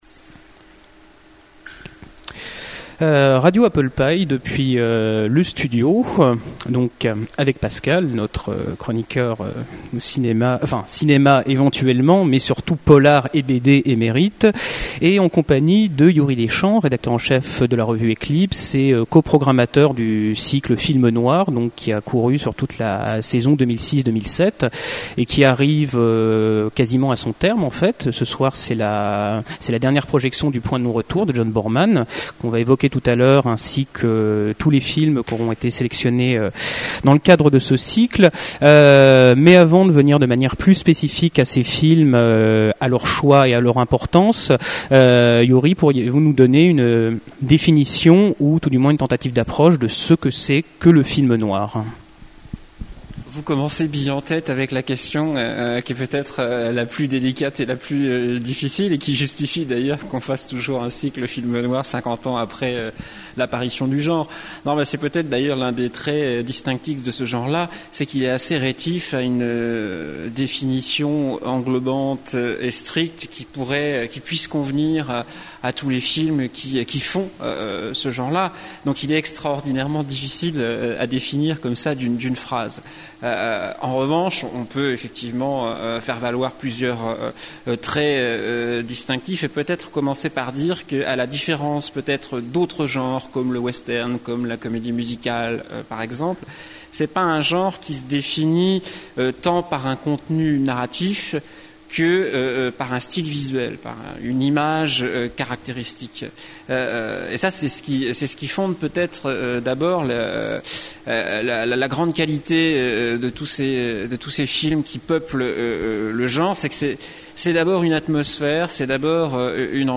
Interview
enregistr�e au Studio, Le Havre